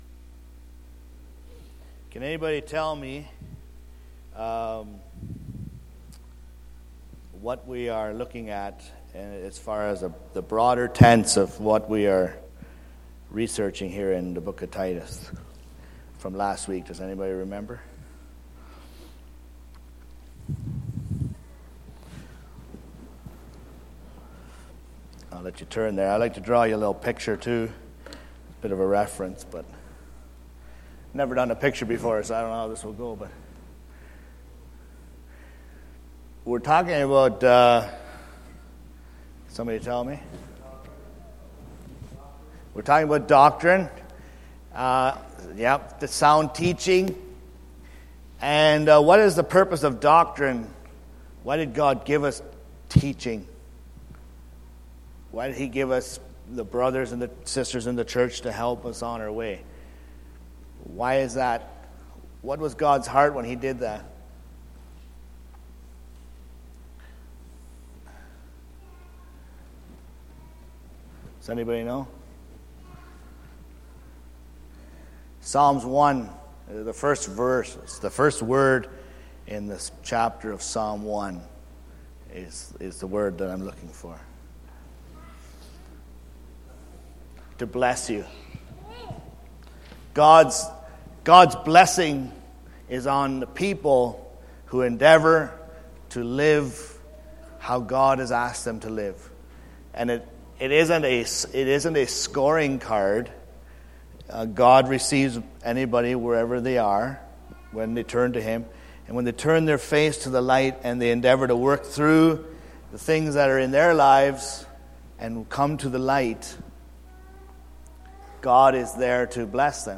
Sunday Morning Bible Study Service Type